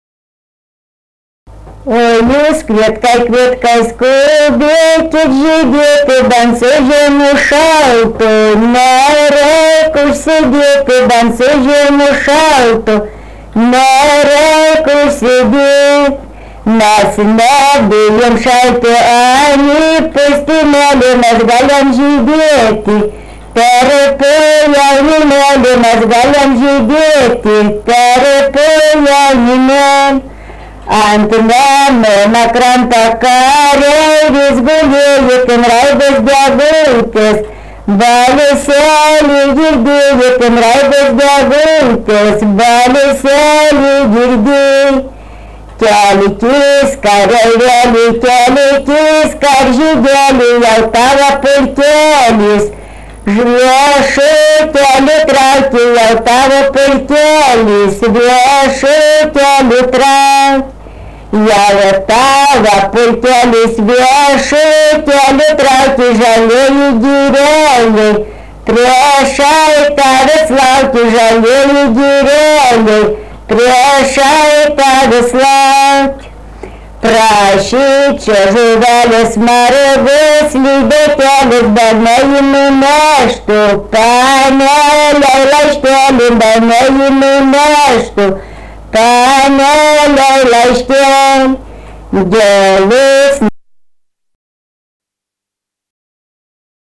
daina, karinė-istorinė
Vieštovėnai
vokalinis